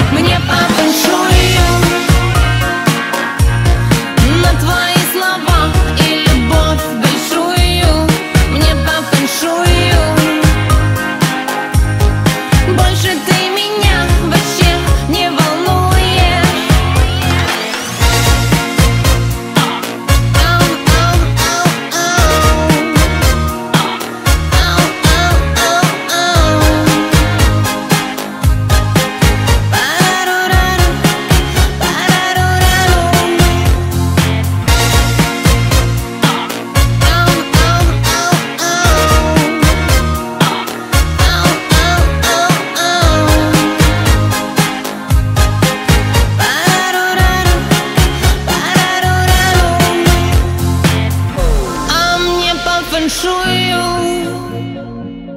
• Качество: 320, Stereo
поп
красивый женский голос